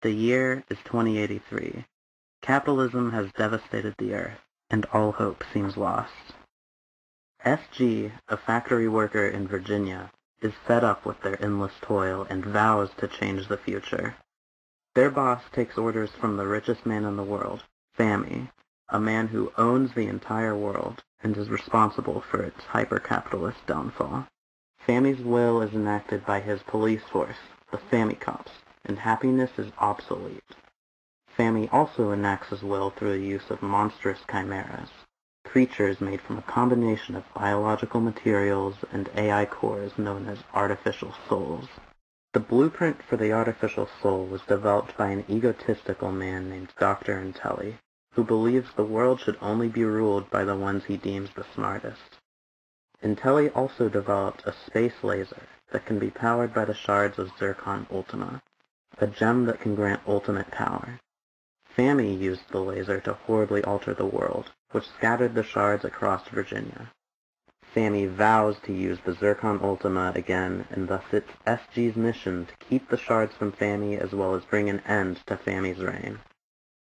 intro_voiceover.ogg